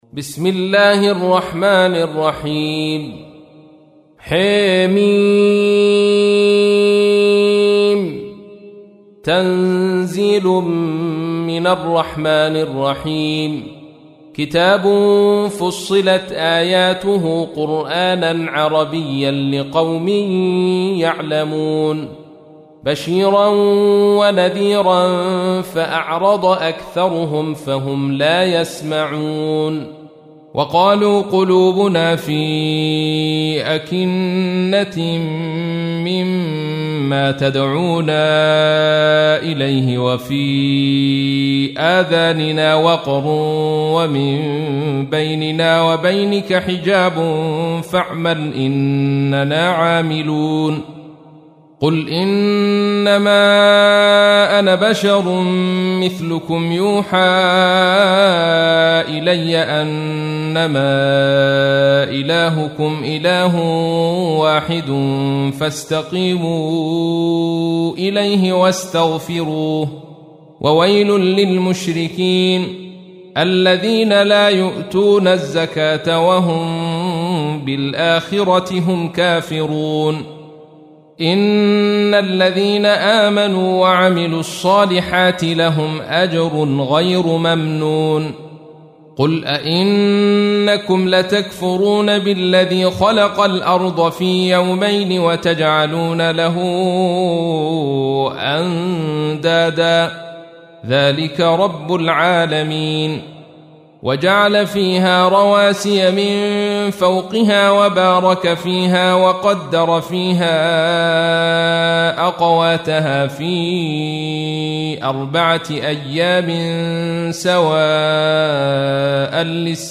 سورة فصلت / القارئ عبد الرشيد صوفي / القرآن الكريم / موقع يا حسين